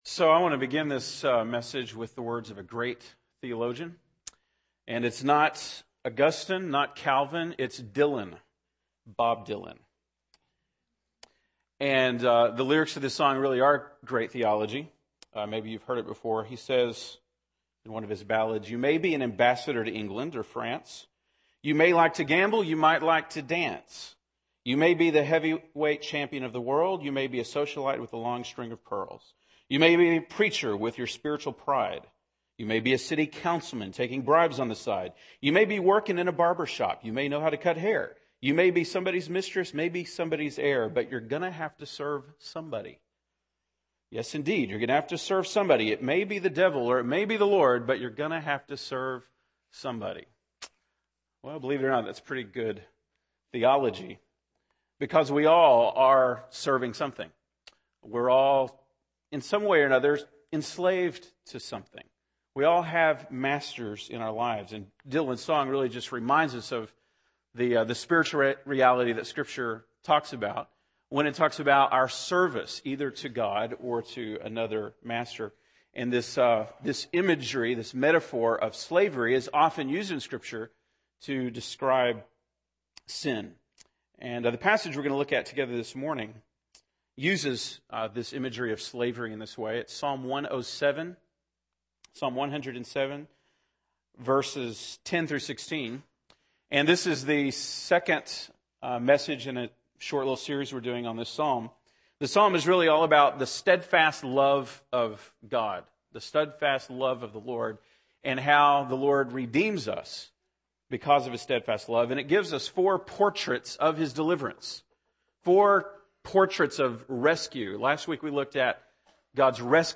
September 5, 2010 (Sunday Morning)